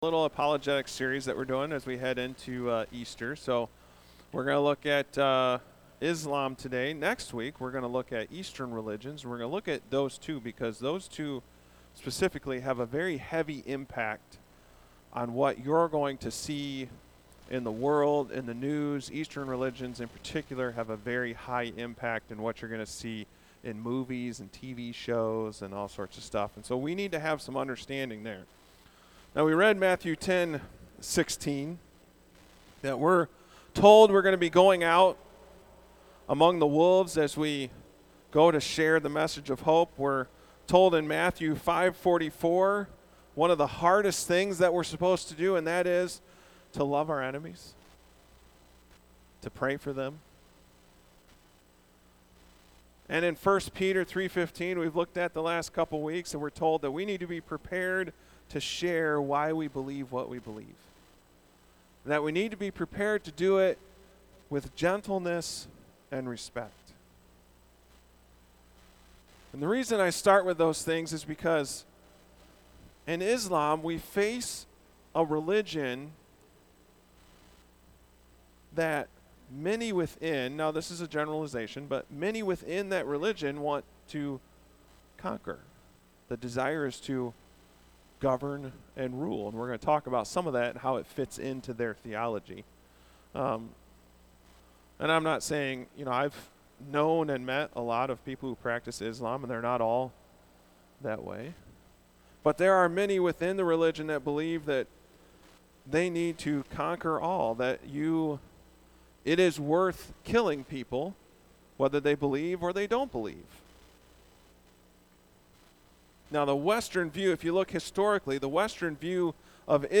Easter Service
A message from the series "Easter Season."